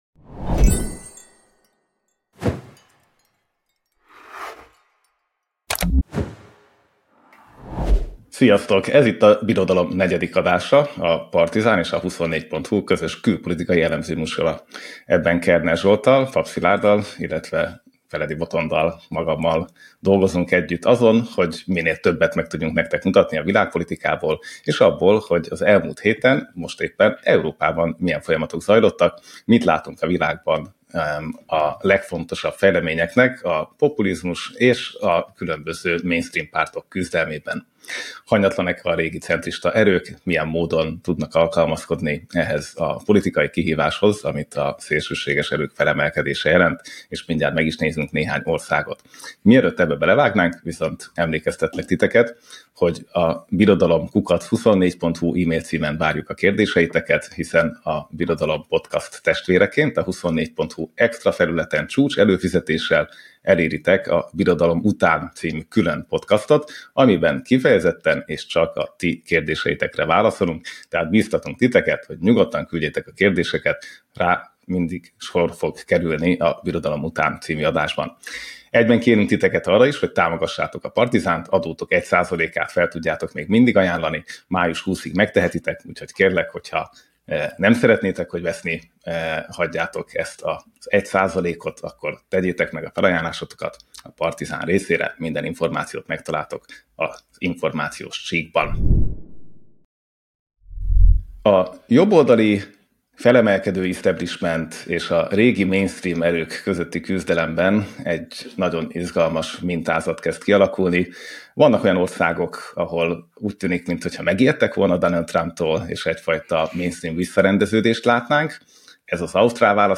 Ferge Zsuzsa a hétvégén ünnepli 90. születésnapját, ebből az alkalomból készítettünk vele életútinterjút.